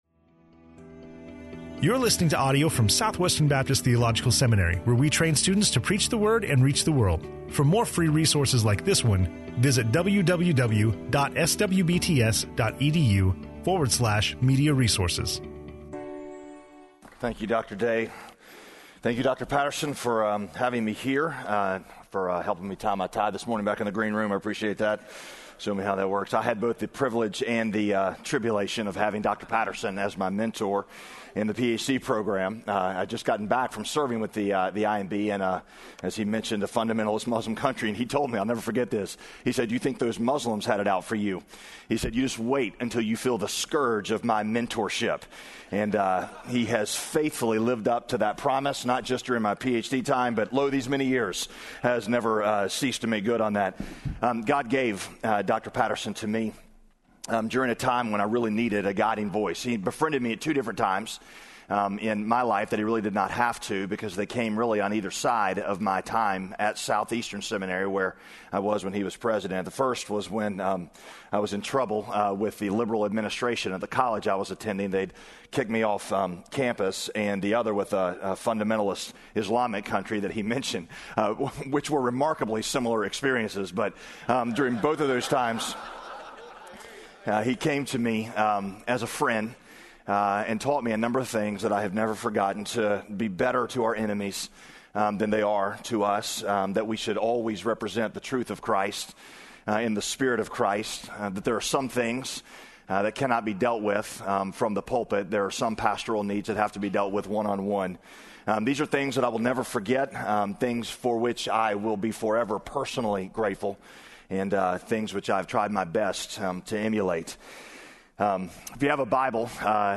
in SWBTS Chapel